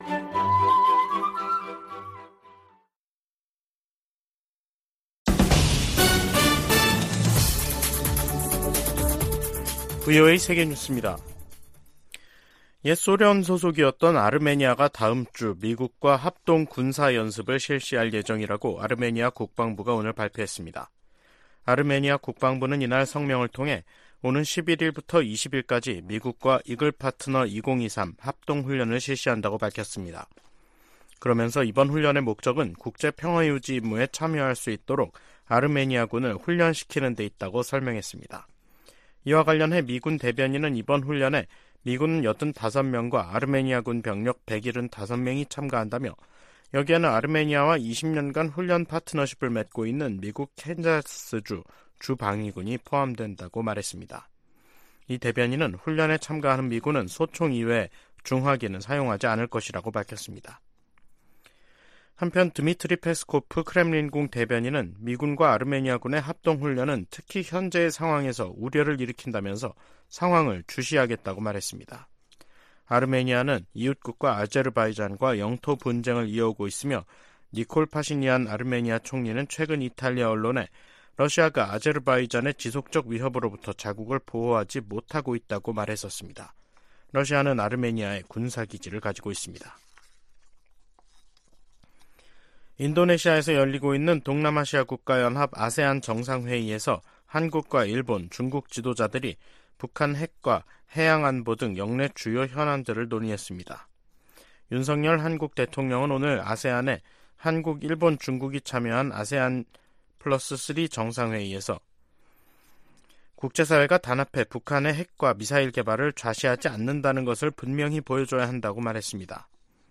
VOA 한국어 간판 뉴스 프로그램 '뉴스 투데이', 2023년 9월 6일 2부 방송입니다. 백악관은 북한과 러시아가 정상 회담을 추진하고 있다는 보도가 나온 가운데 양국 간 무기 협상을 중단하라고 촉구했습니다. 윤석열 한국 대통령도 북-러 군사협력을 시도하지 말라고 요구했습니다. 김정은 북한 국무위원장과 블라디미르 푸틴 러시아 대통령은 정상회담에서 양국 군사협력을 새로운 차원으로 진전시키는 중요한 합의를 발표할 것으로 미국 전문가들이 내다봤습니다.